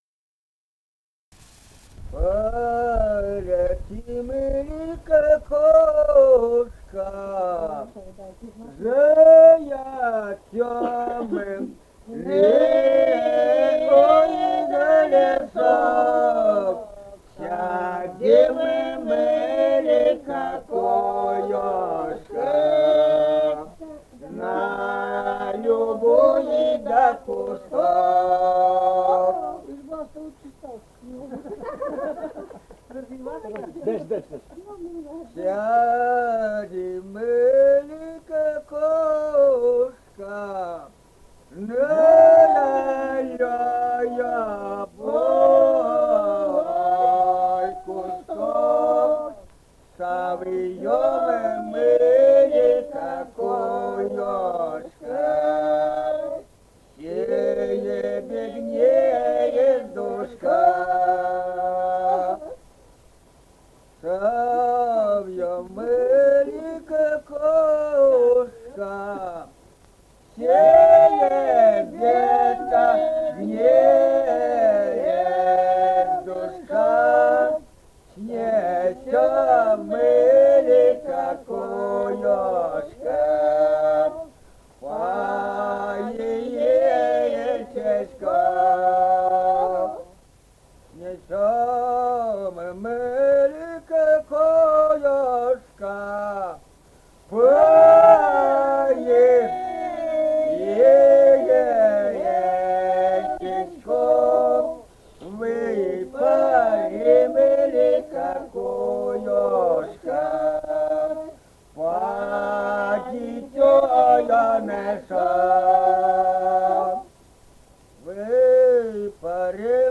Республика Казахстан, Восточно-Казахстанская обл., Катон-Карагайский р-н, с. Коробиха, июль 1978.